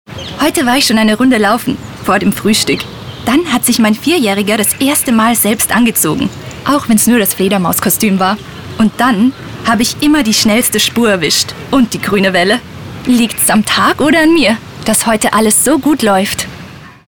Locutor
Hablante nativo
austríaco